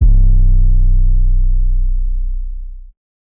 TS 808_6.wav